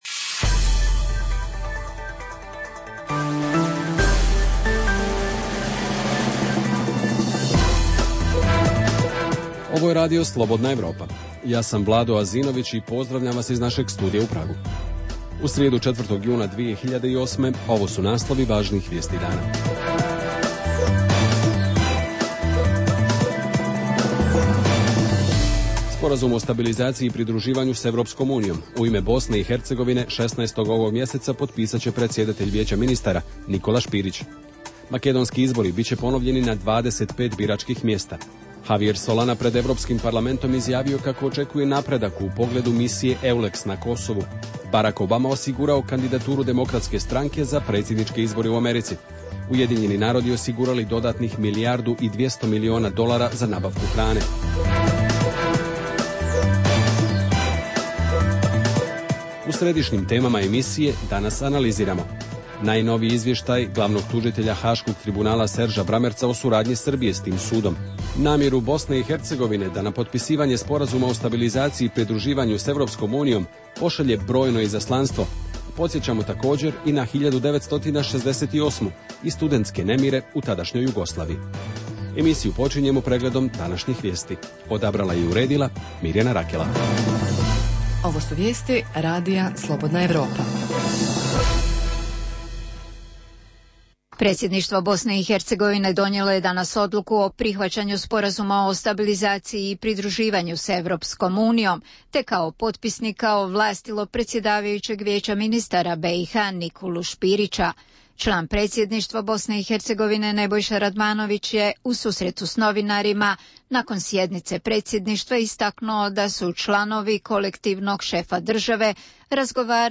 Emisija o dešavanjima u regionu (BiH, Srbija, Kosovo, Crna Gora, Hrvatska) i svijetu. Prvih pola sata emisije sadrži najaktuelnije i najzanimljivije priče o dešavanjima u zemljama regiona i u svijetu (politika, ekonomija i slično).